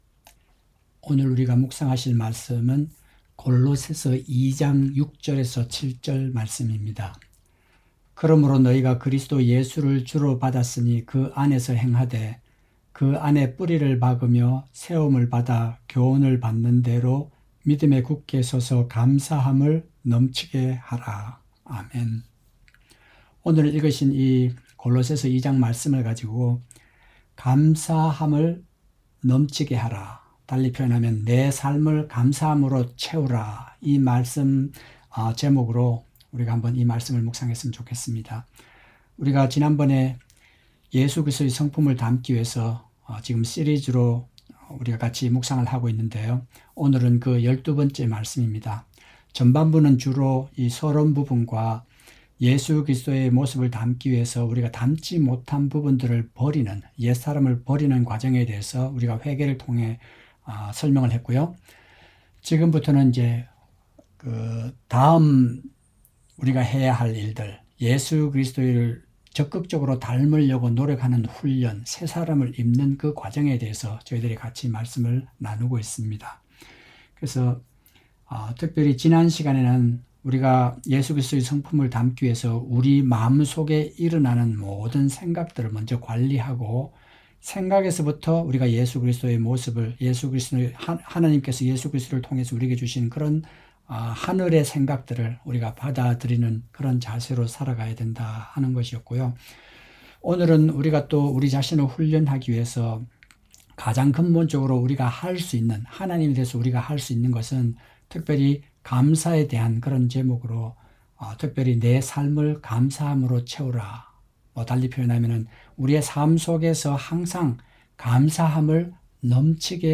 새벽설교